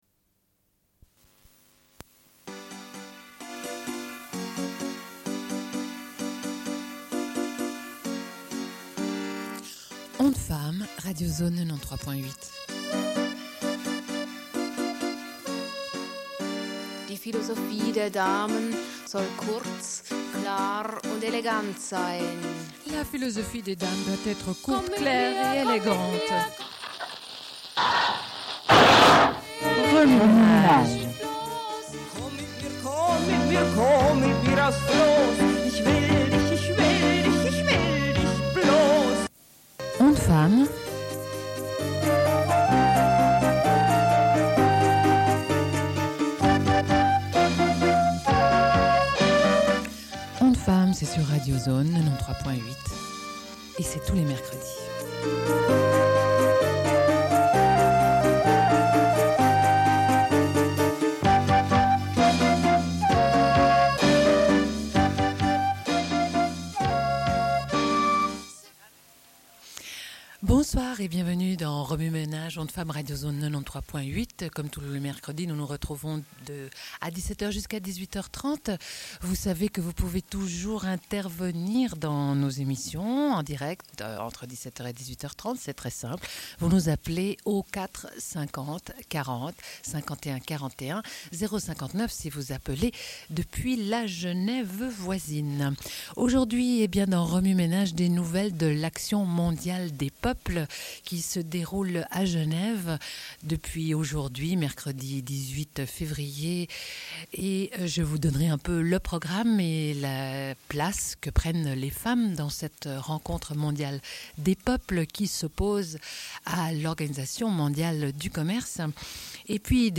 Une cassette audio, face A
Radio Enregistrement sonore